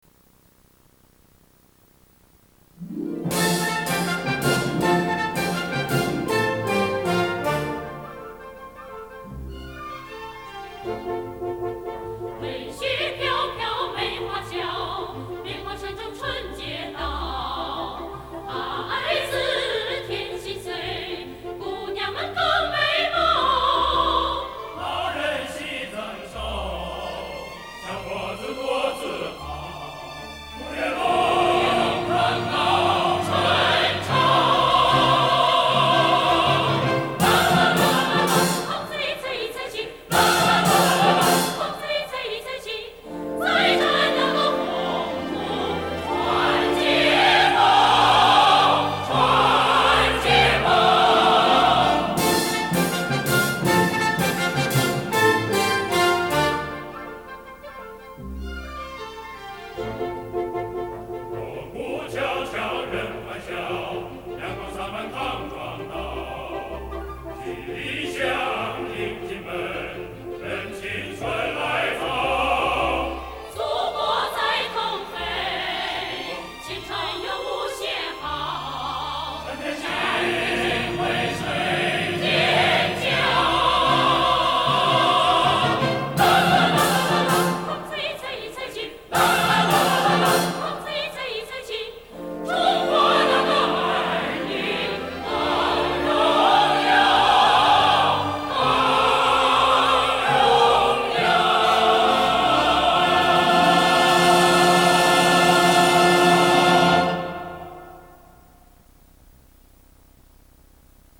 【磁带】